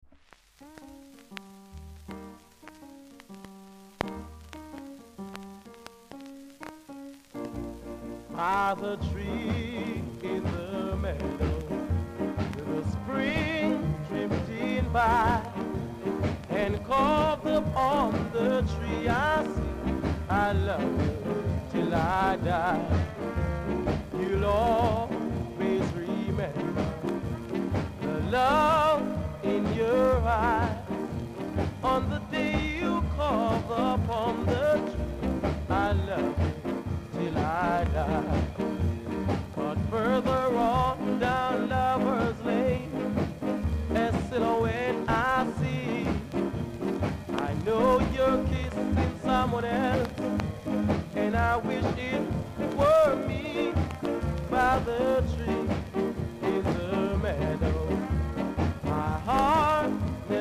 ※パチノイズが少しあります。
コメント NICE SKA!!